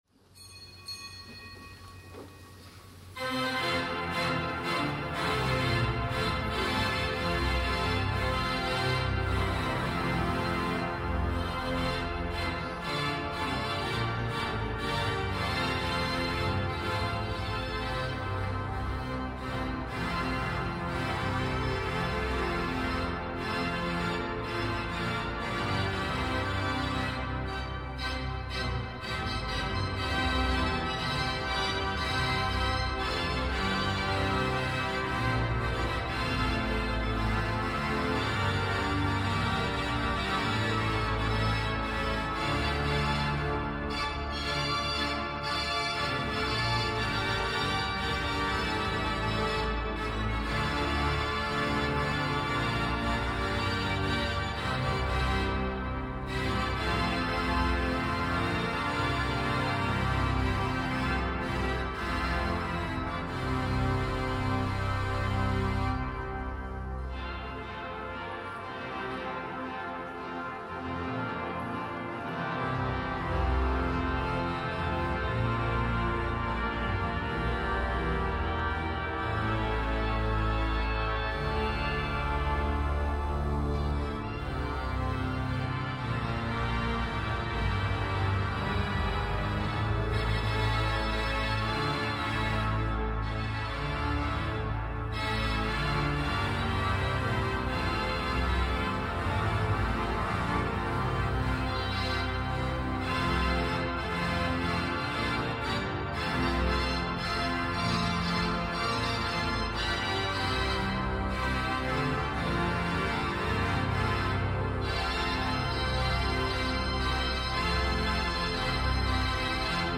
Festmesse anlässlich des 150. Geburtstags von Konrad Adenauer